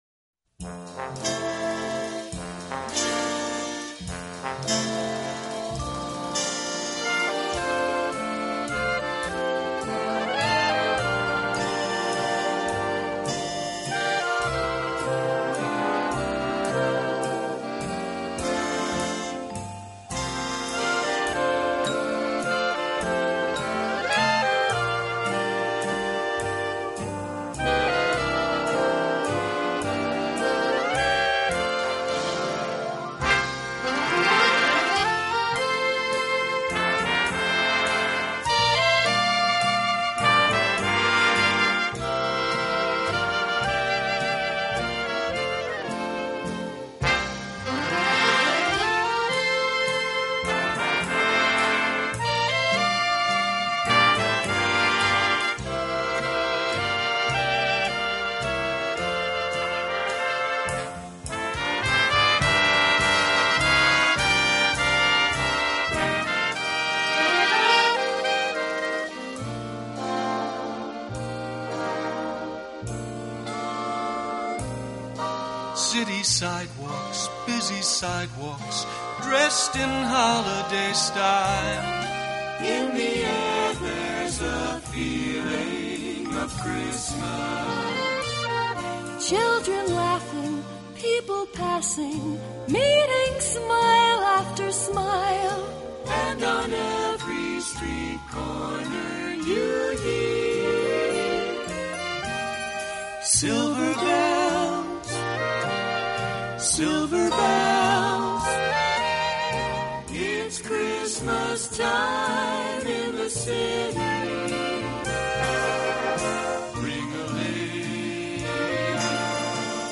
【轻音爵士】